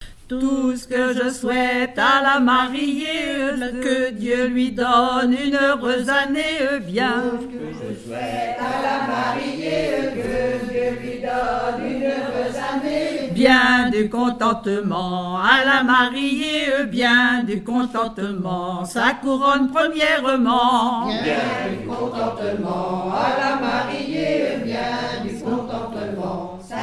circonstance : fiançaille, noce ;
Genre énumérative
chansons et commentaires sur les chansons
Pièce musicale inédite